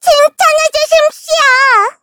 Taily-Vox_Victory_kr_a.wav